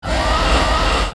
File:Dagahra anime roar.mp3
Dagahra_anime_roar.mp3